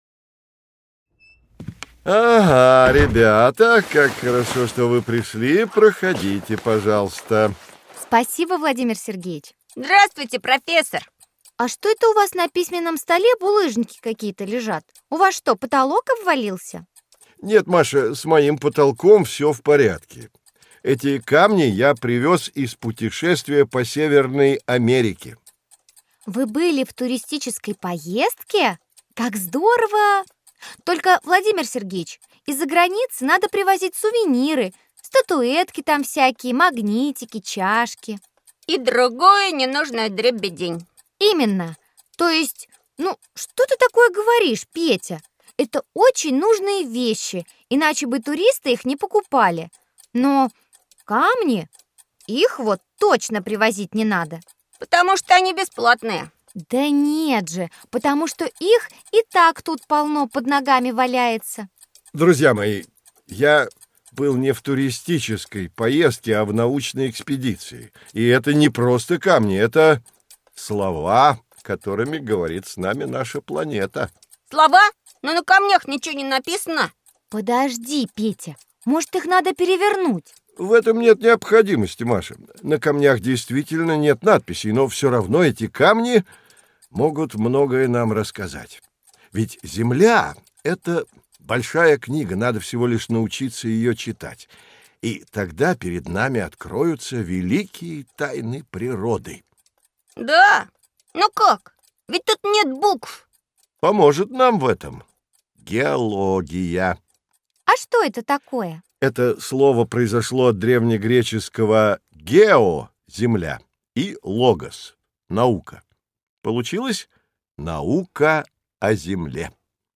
Аудиокнига Естествознание: Занимательная геология | Библиотека аудиокниг